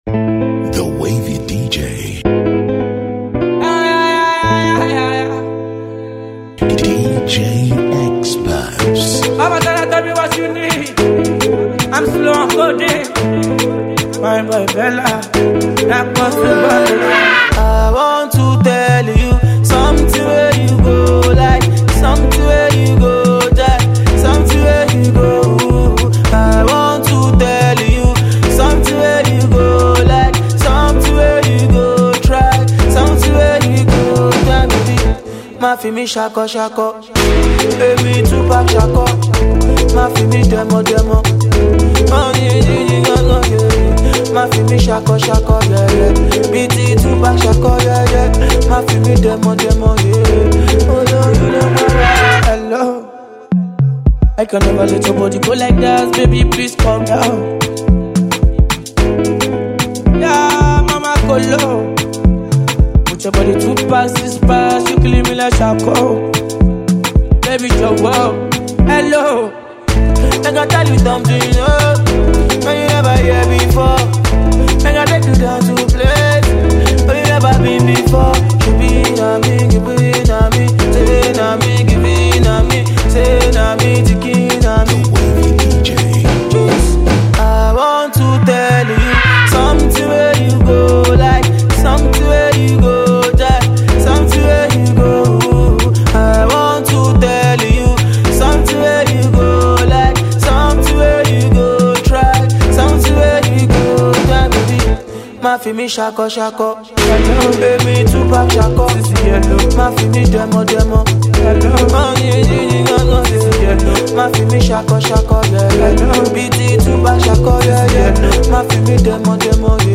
a rhythmic tune